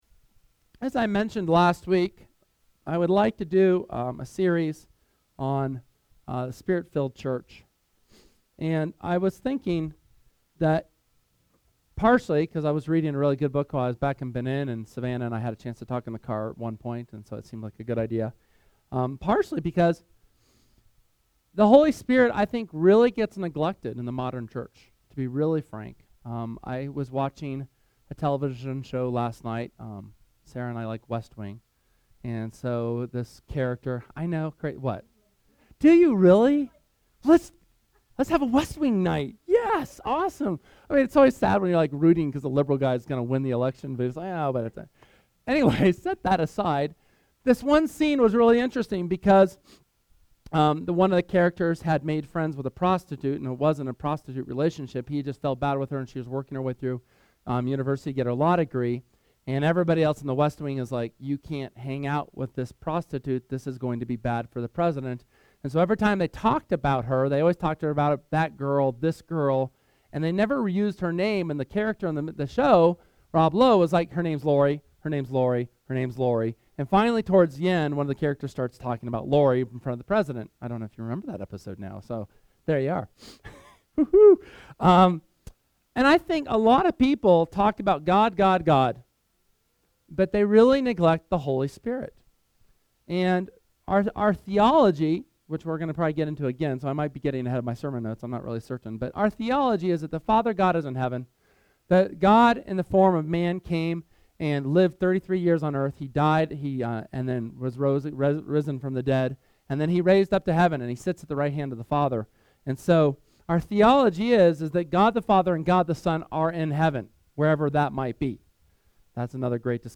SERMON: The Necessity of the Holy Spirit (HS #1)